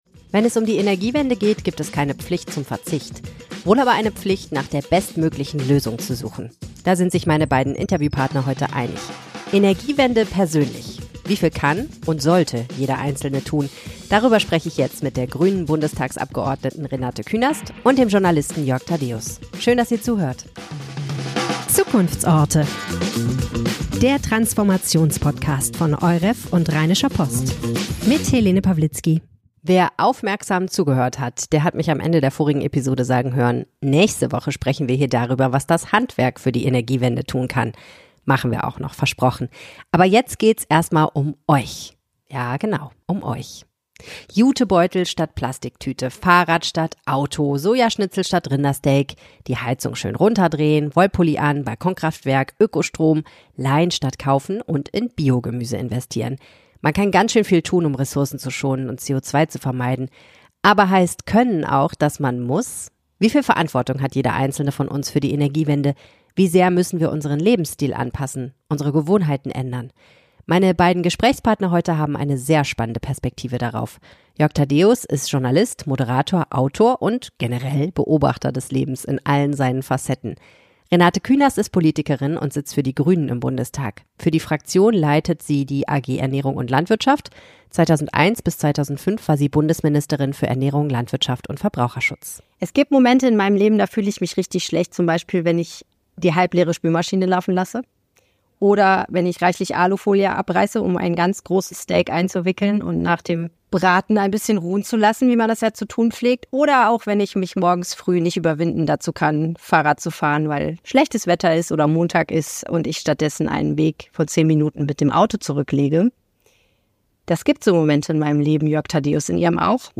Wieviel Verantwortung hat jeder von uns für die Energiewende? Ein Gespräch über Pflicht und Genuss.